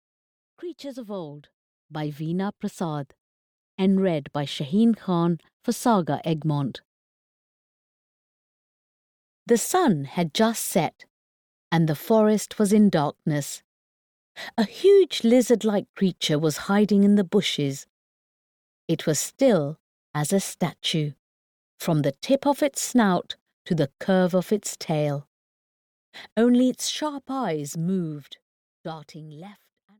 Creatures of Old (EN) audiokniha
Ukázka z knihy